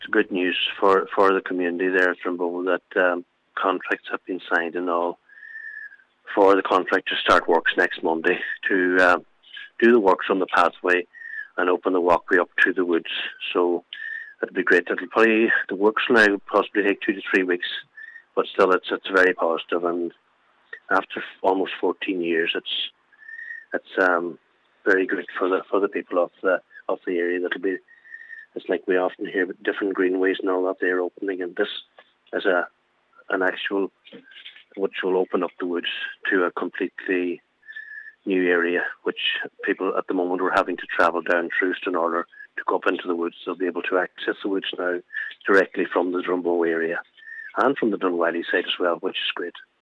The news has been welcomed by Councillor Martin Harley.